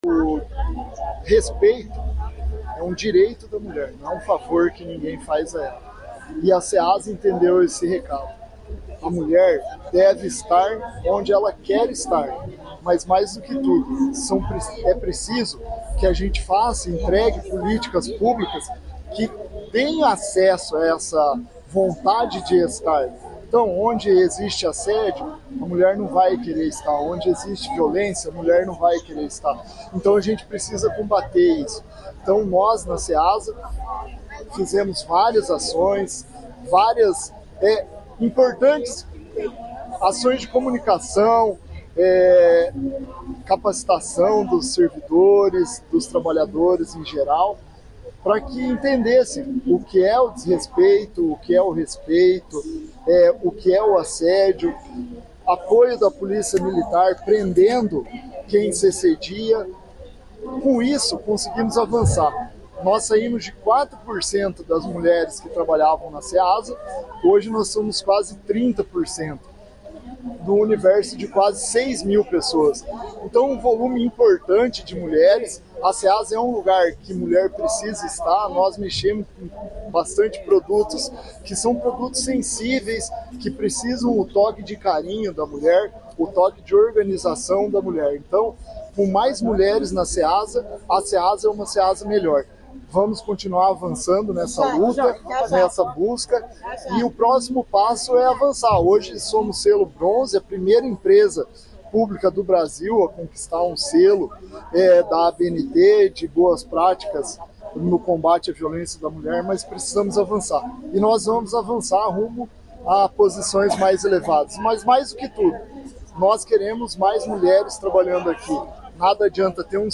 Sonora do diretor-presidente da Ceasa Paraná, Éder Bublitz, sobre o selo de boas práticas no combate à violência contra a mulher